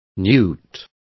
Complete with pronunciation of the translation of newts.